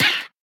assets / minecraft / sounds / mob / parrot / death3.ogg
death3.ogg